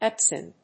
/ˈɛpsʌn(米国英語), ˈepsʌn(英国英語)/